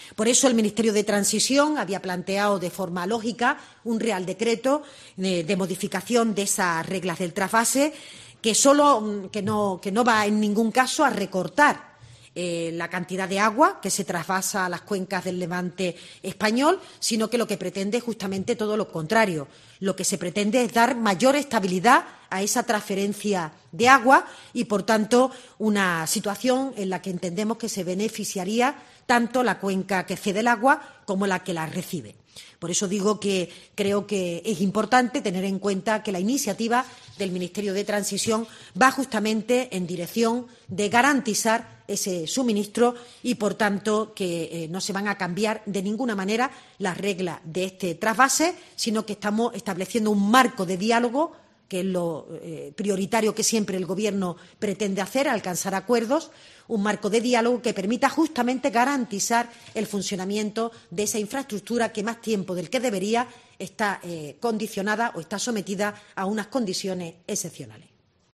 PINCHA Y ESCUCHA AQUÍ LO QUE DIJO M. JESÚS MONTERO, MINISTRA PORTAVOZ